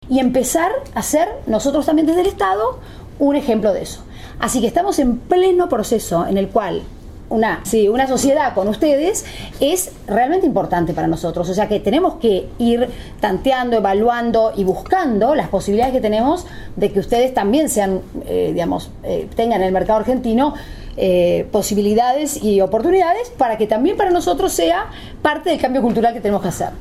la vicepresidenta ofreció formato MP3 audio(0,51 MB) sin dudar la colaboración de su gobierno con el Grupo Social ONCE para iniciar el proceso de cambio en Argentina, a través de una estrecha cooperación con beneficios mutuos.